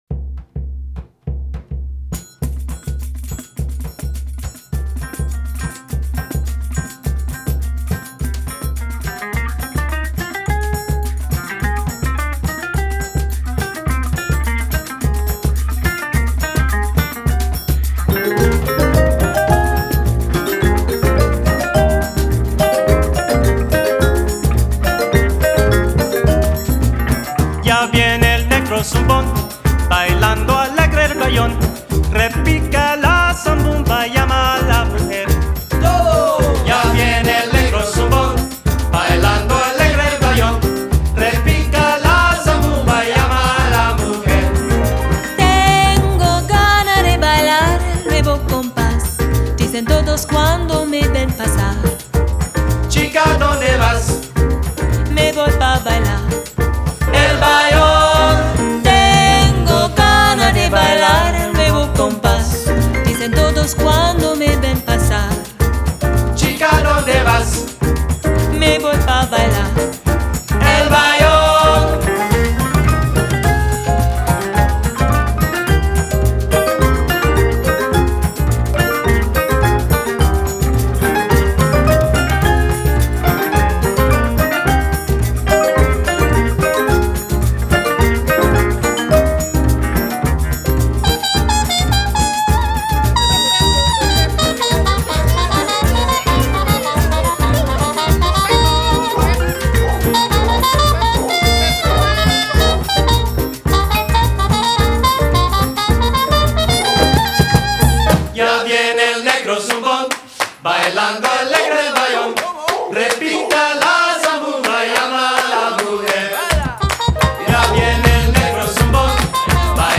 Genre: Jazz,Latin